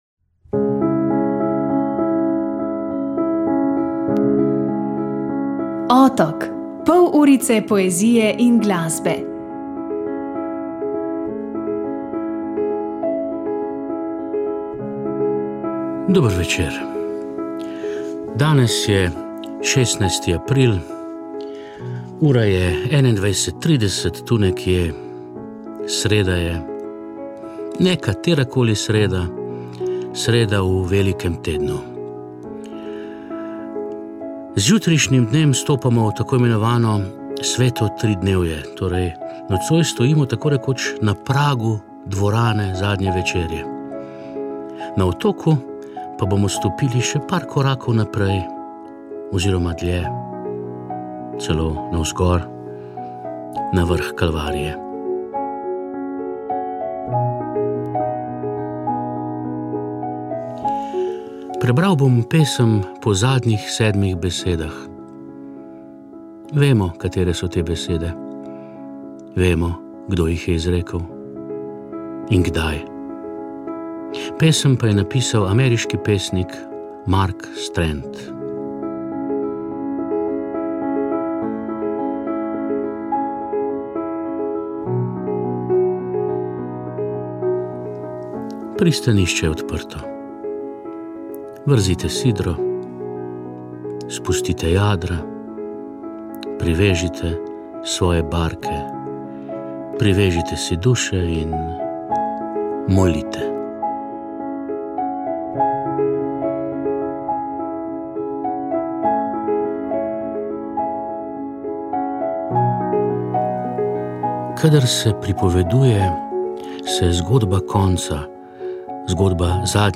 V Cankarjevem domu v Ljubljani je novembra potekal ciklus predavanj z naslovom: Dostojevski in jaz. Naslov je vzet po zborniku, ki je izšel pri založbi LUD Literatura.